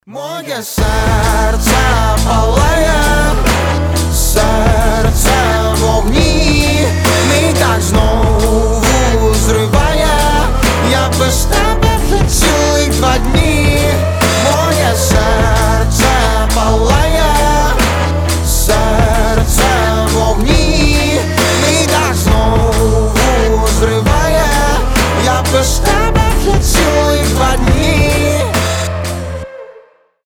• Качество: 320, Stereo
RnB